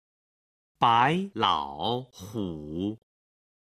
动物 (dòng wù)